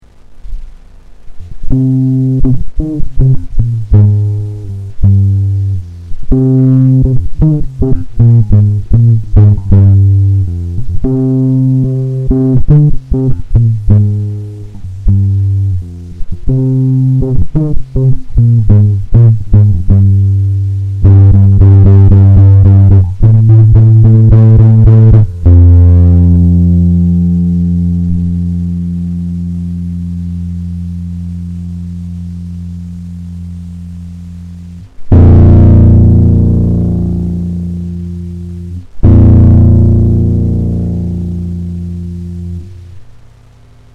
Laser-Akkustik-Gitarre
Es ist Zeit rauszufinden, was man einer alten Konzertgitarre mit einem Laserpointer und eine Photodiode für Töne entlocken kann. Als Verstäker benutzen wir die Soundkarte des Computers mit nachgeschalteten Aktivboxen. So lässt sich eine Verstärkung erreichen, die Rock-Gitarren-Feeling aufkommen lässt.